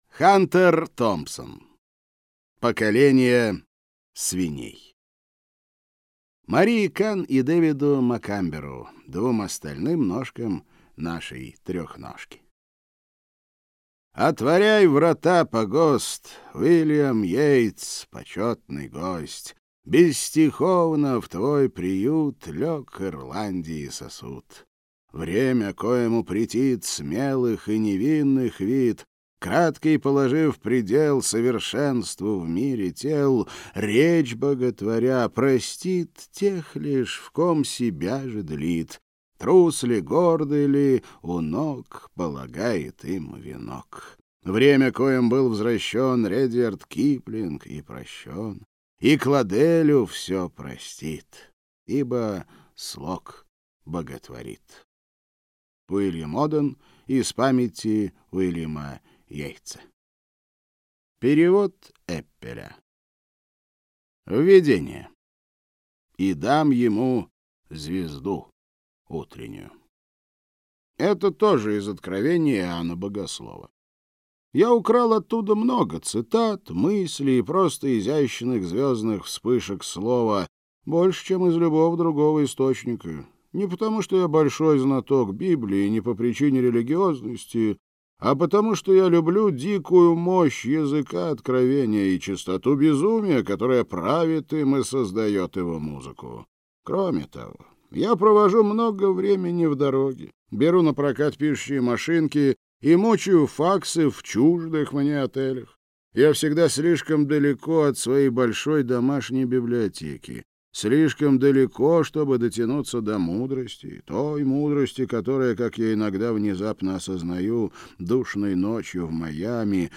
Аудиокнига Поколение свиней | Библиотека аудиокниг